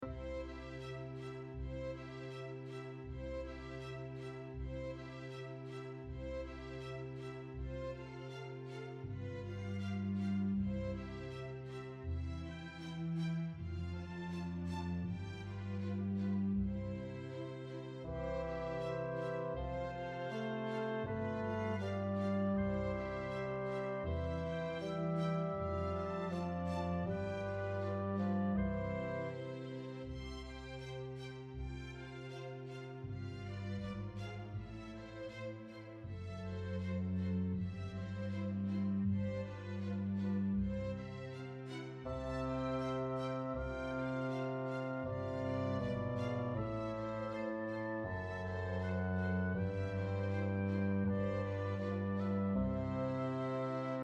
Loop Full Score